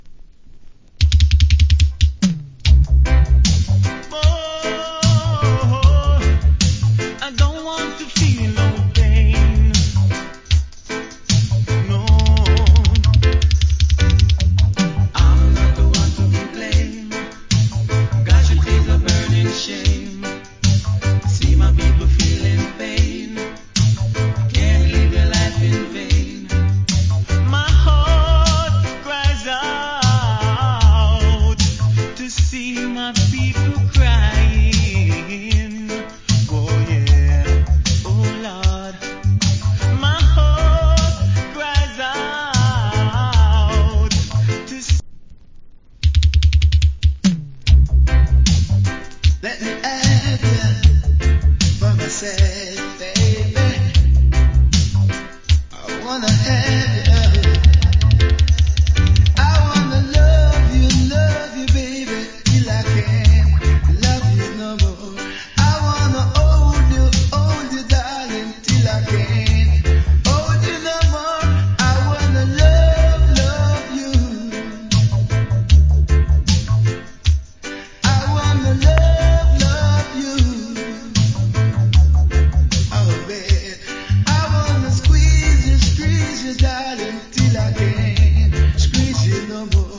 80's. Cool UK Lovers.